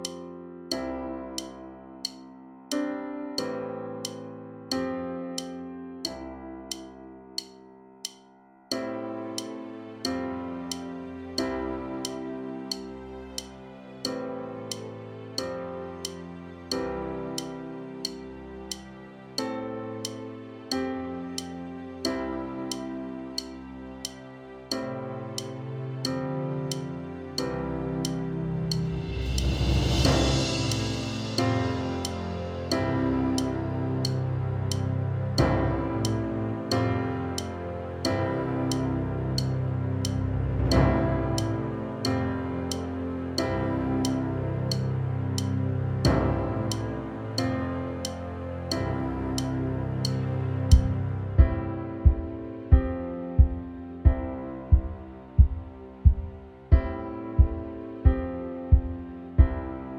Minus Main Guitar For Guitarists 3:27 Buy £1.50